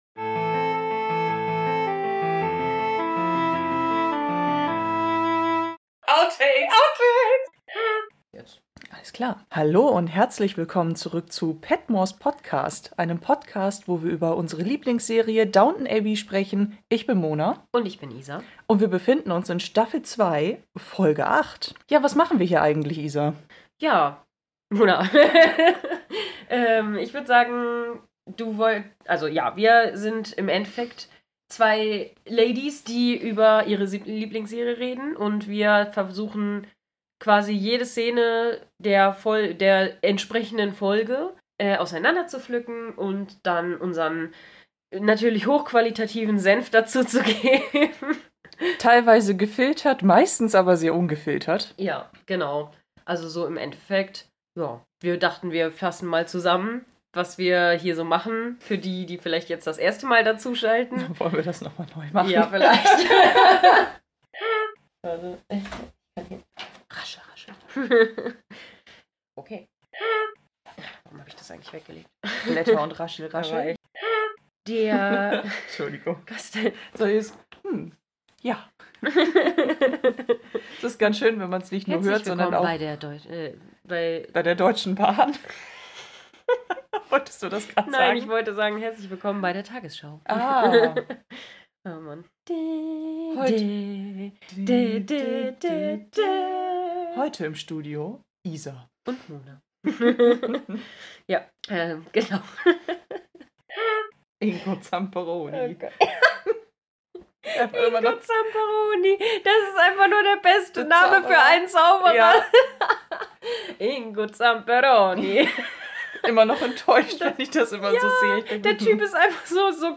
s02e08-Outtakes
Die vielleicht leicht verkaterten und sehr müden Outtakes zu